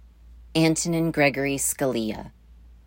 Captions English pronunciation of Justice Antonin Scalia's full name